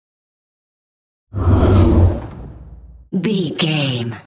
Whoosh fast trailer
Sound Effects
Fast paced
Fast
futuristic
intense